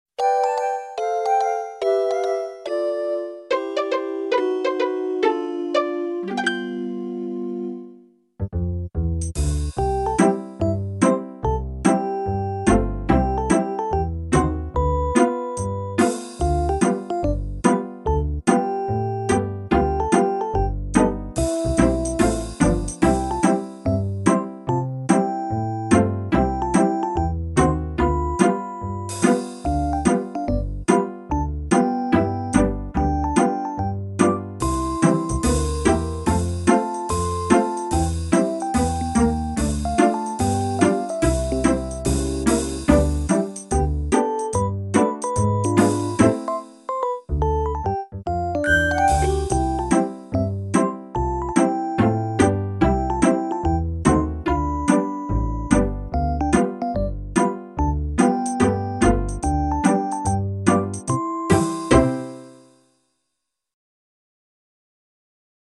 KARAOKE VERSION: 09-AudioTrack 09